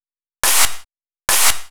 VTDS2 Song Kit 10 Rap U Got Me Rocking Clap.wav